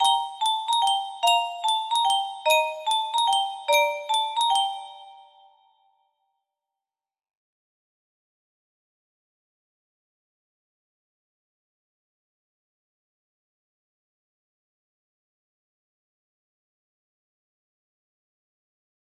Carol of the Bells (short) music box melody